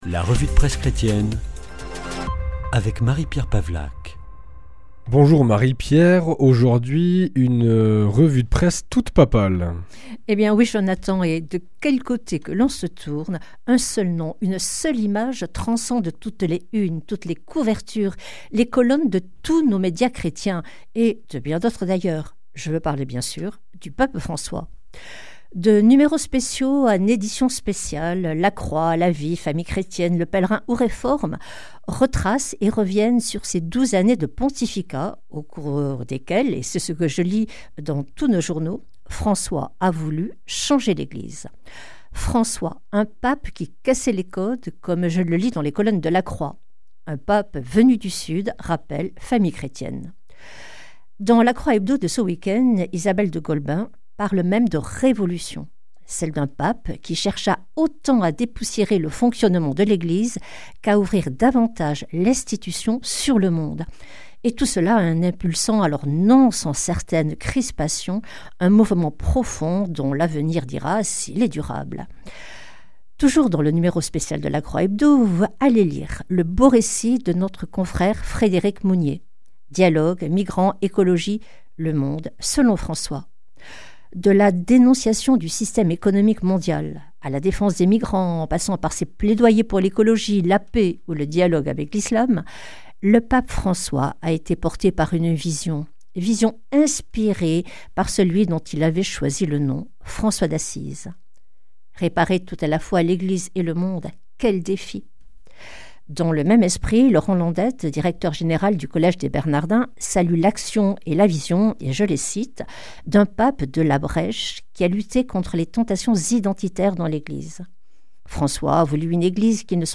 Revue de presse
Une émission présentée par